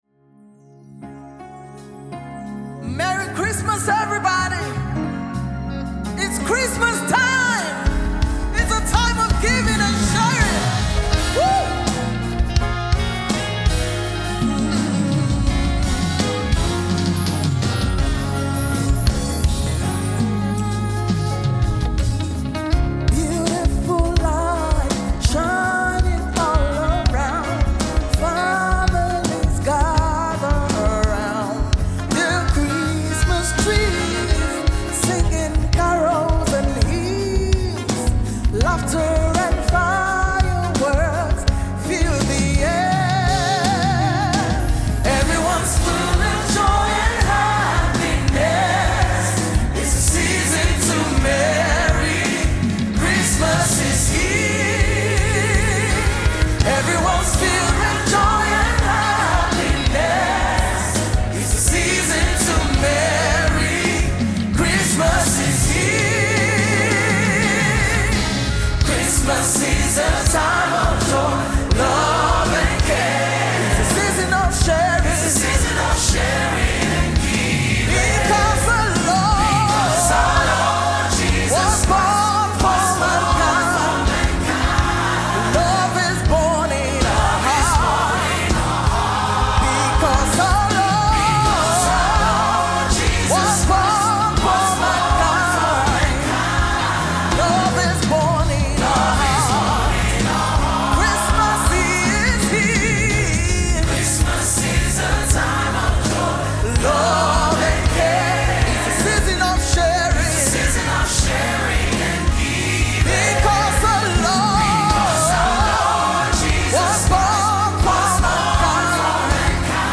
Christmas Songs